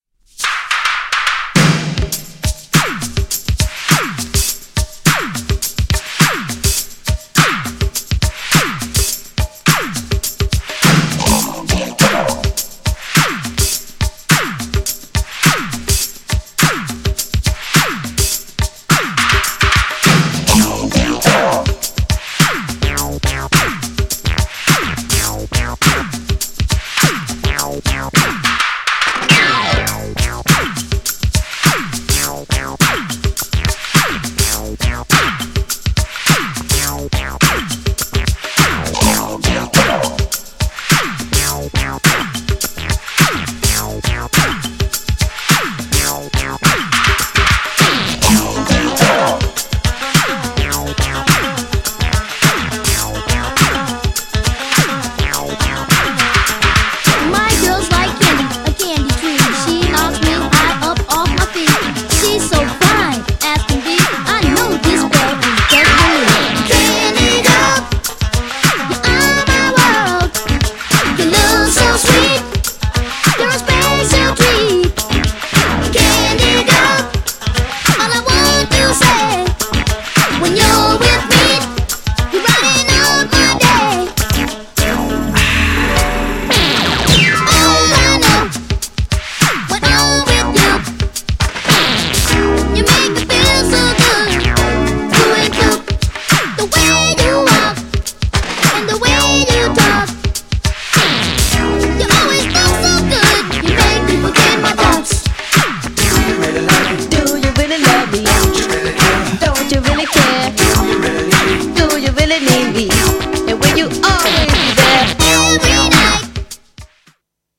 エレクトロなDISCO MIXに!!
GENRE Dance Classic
BPM 101〜105BPM